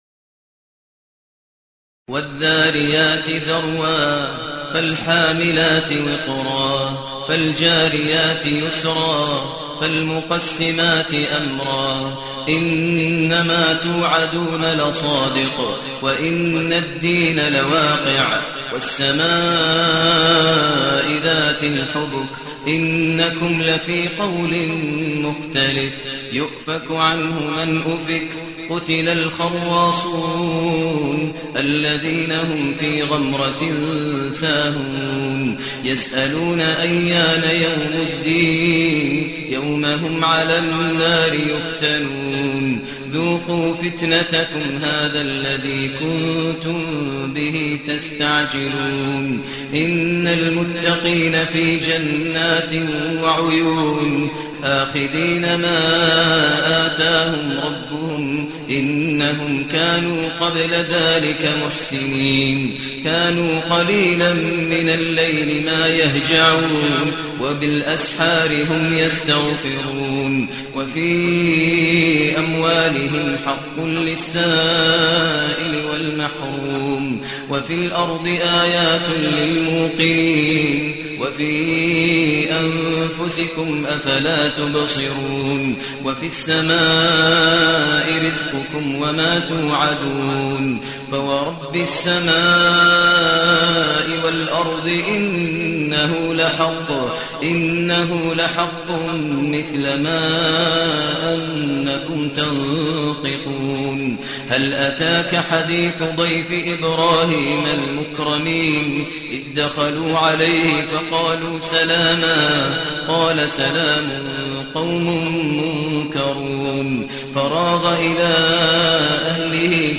المكان: المسجد الحرام الشيخ: فضيلة الشيخ ماهر المعيقلي فضيلة الشيخ ماهر المعيقلي الذاريات